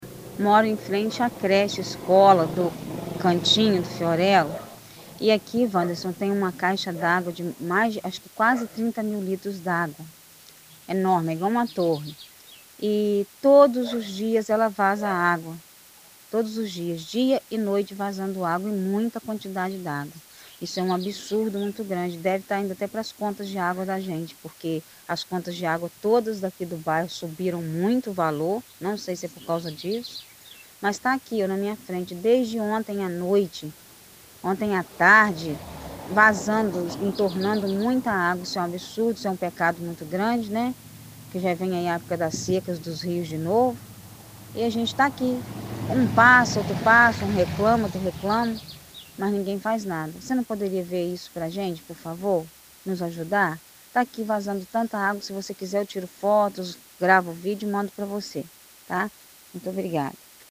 Ouça o relato da moradora: